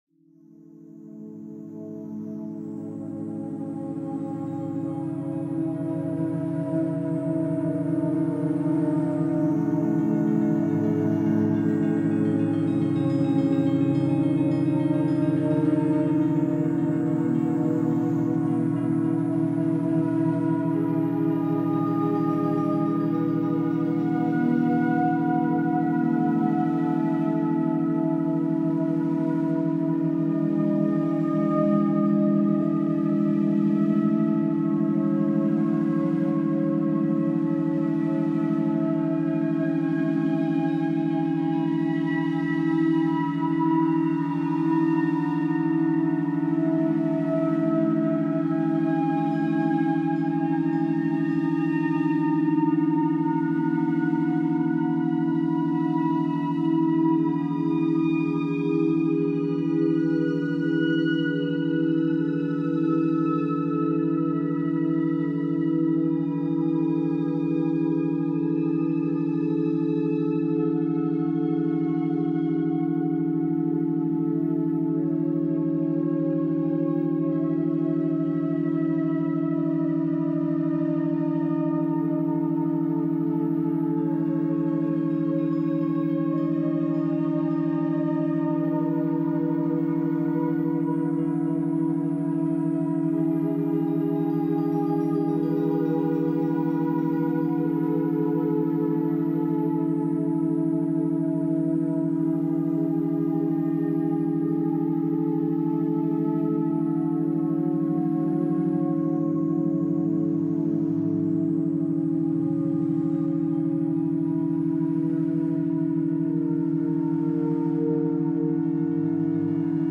Brain Energizer – 40 Hz Gamma Waves for Mental Clarity